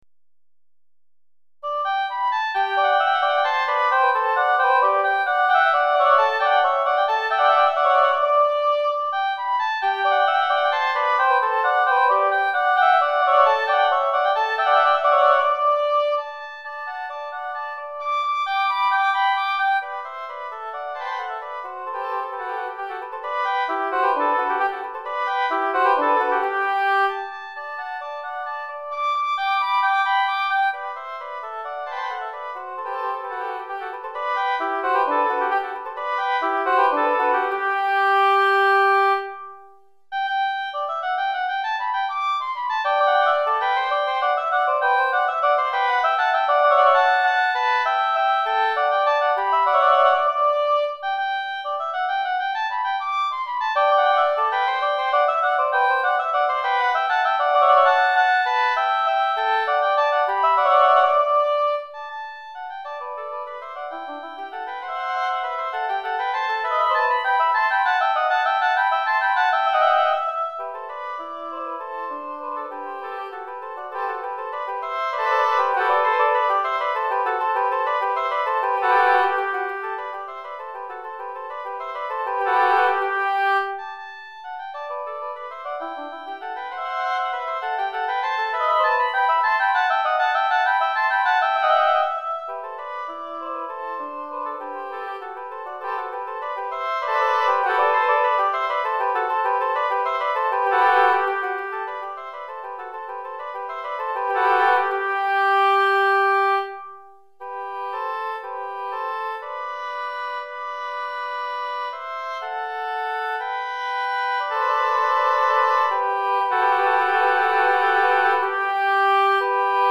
Répertoire pour Hautbois - 2 Hautbois